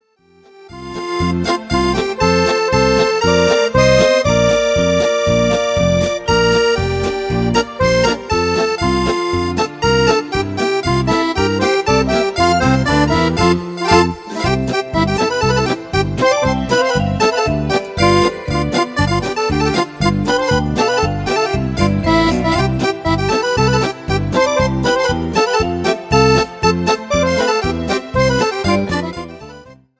POLKA  (2.45)